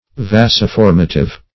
Search Result for " vasoformative" : The Collaborative International Dictionary of English v.0.48: Vasoformative \Vas`o*form"a*tive\, a. [L. vas a vessel + formative.]
vasoformative.mp3